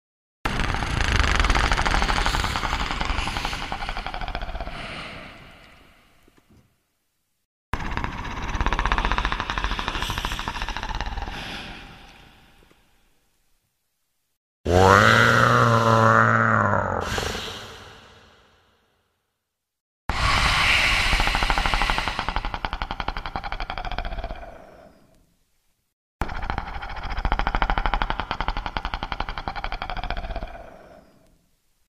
Звуки чужого
Звуки издаваемые чужим